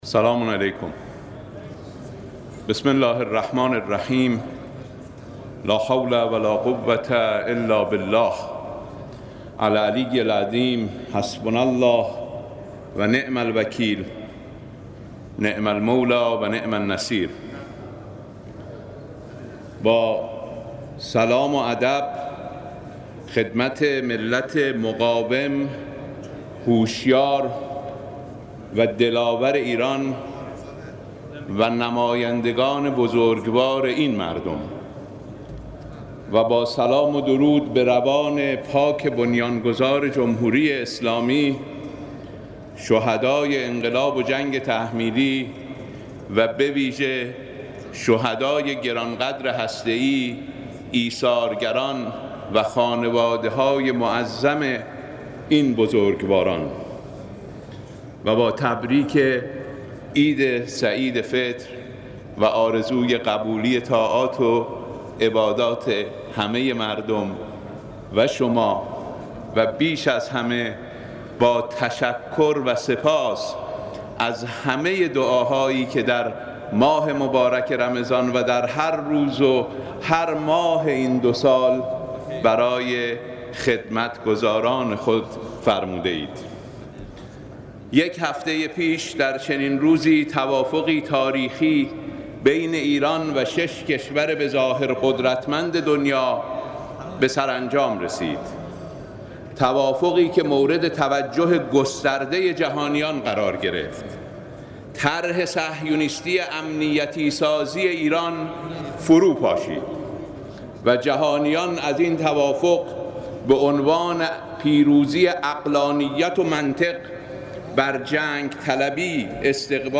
خبرگزاری تسنیم: صوت صحبت‌های وزیر امور خارجه دکتر محمدجواد ظریف و دکتر علی‌اکبر صالحی رئیس سازمان انرژی اتمی در صحن علنی مجلس شورای اسلامی منتشر می‌شود.